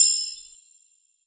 chimes_magic_bell_ding_4.wav